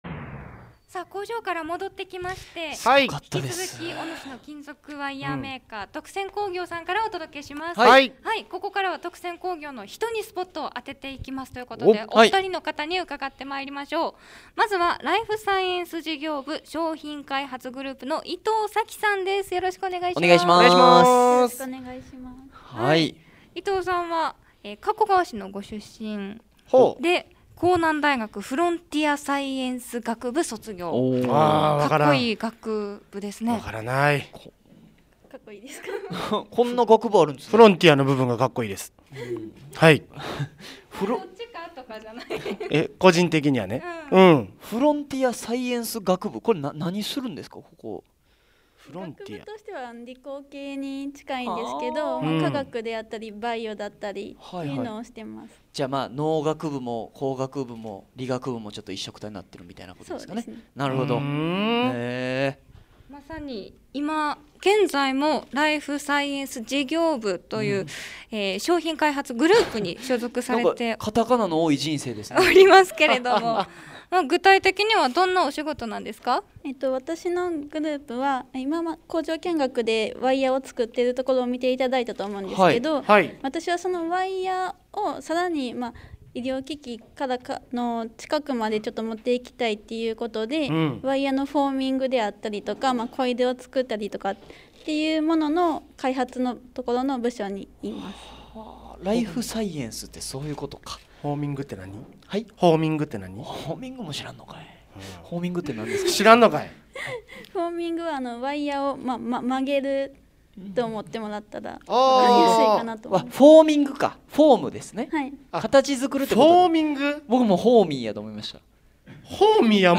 甲子園球場約10個分におよぶ、広大で大迫力な工場を見学した後は、2人の先輩社会人に話をうかがった。